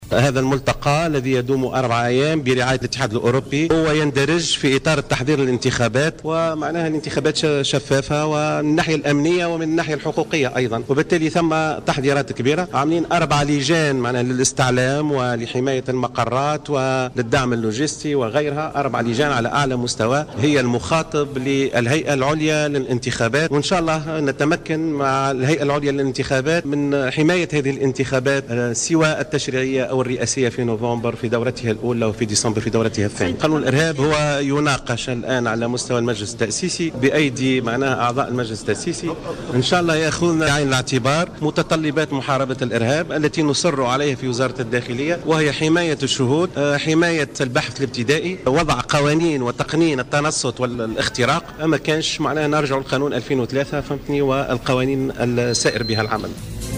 قال وزير الداخلية لطفي بن جدو اليوم الثلاثاء خلال إشرافه على دورة تكوينية في مجال الانتخابات بالتعاون مع مفوضية الاتحاد الأوروبي إنه تم الكشف عن أطراف متورطة في تهريب الأسلحة.